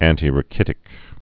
(ăntē-rə-kĭtĭk, ăntī-)